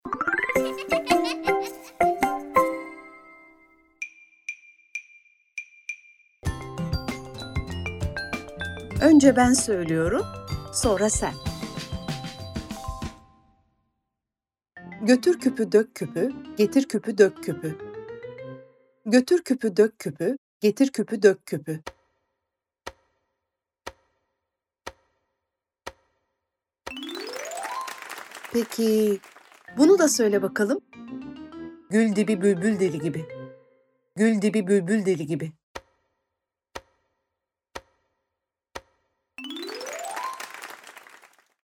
Söylerken hızına yetişemeyeceğiniz, tekrar ederken gülmekten karnınızın ağrıyacağı "Tekerlemeler" serimiz sizlerle!